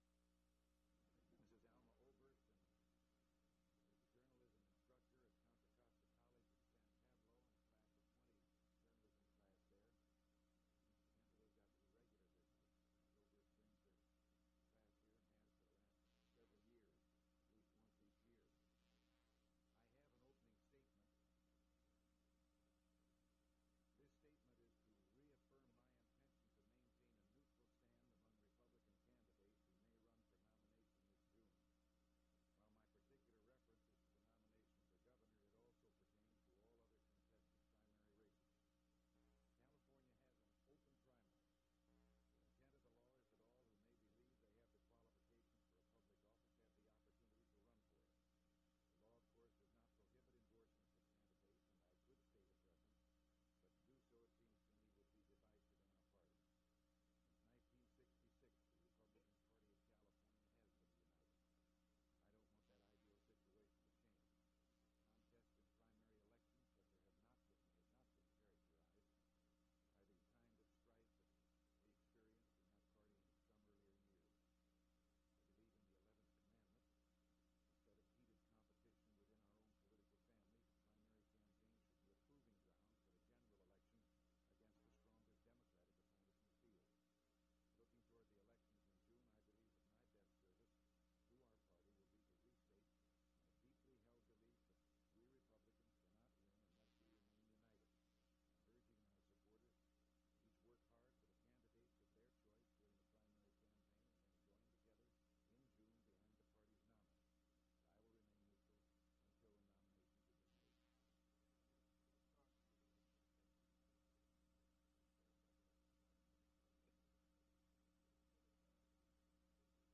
Governor Ronald Reagan News Conference
Audio Cassette Format.